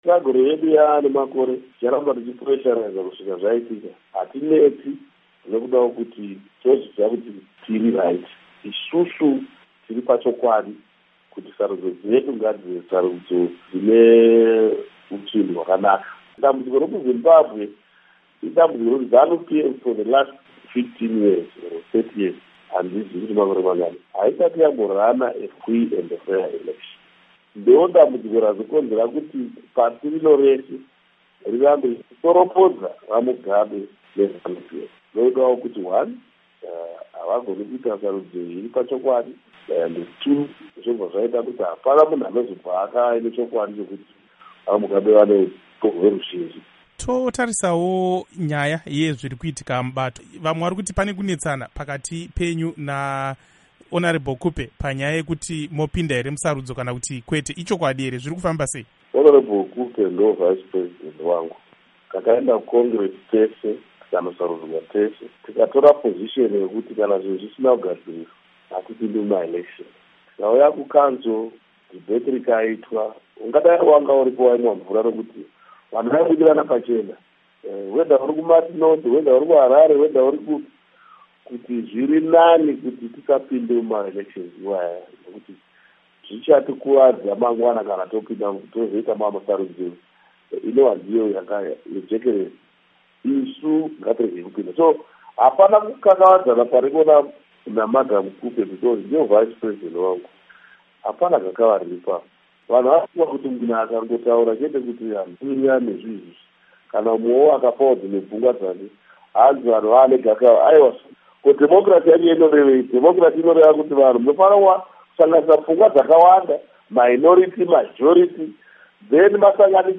Hurukuro naVaMorgan Tsvangiai